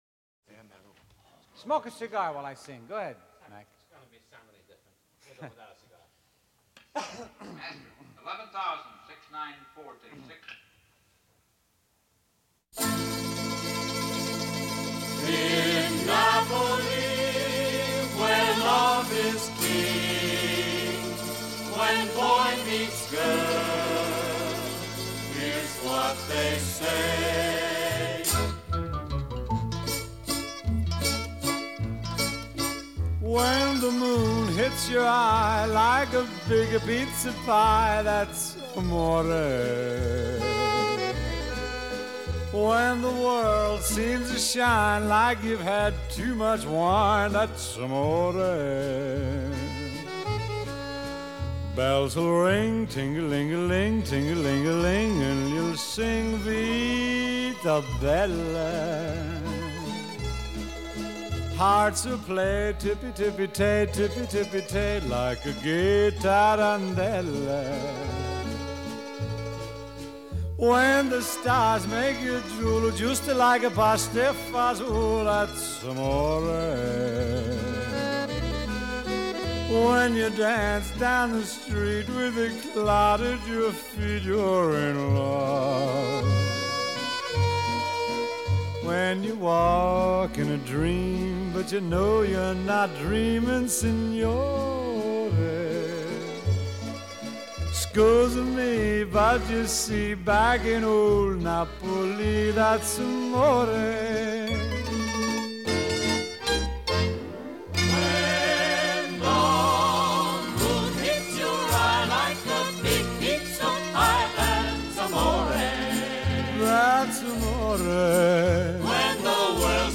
Traditional Pop, Jazz, Vocal